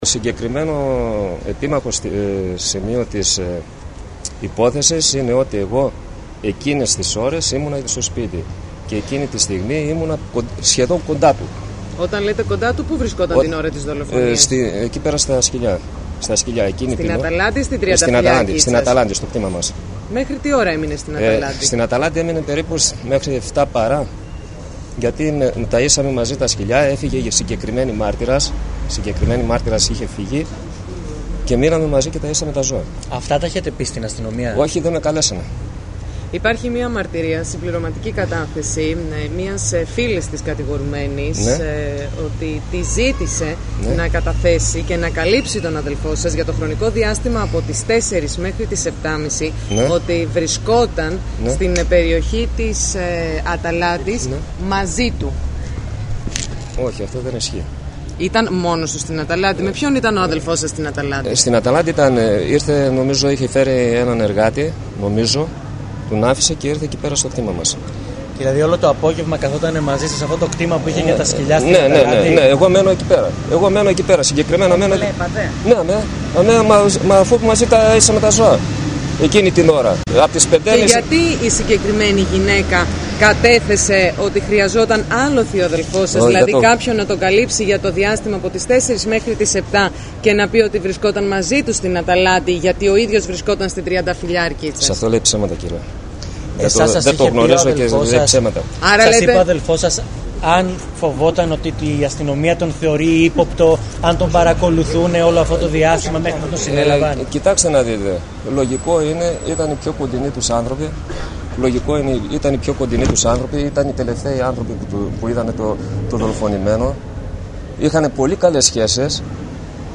ΑΚΟΥΣΤΕ ΤΟ ΗΧΗΤΙΚΟ ΑΠΟ ΤΙΣ ΔΗΛΩΣΕΙΣ ΤΟΥ ΑΔΕΡΦΟΥ ΤΟΥ 56ΧΡΟΝΟΥ